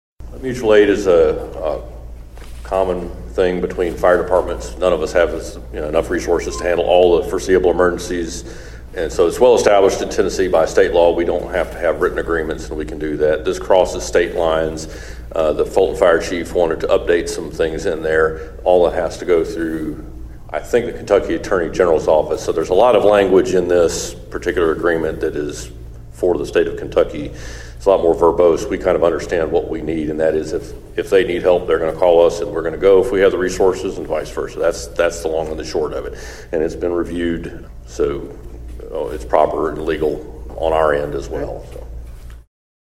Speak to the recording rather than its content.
Union City Council members were informed of a mutual aid proposal with the City of Fulton during Tuesday’s meeting.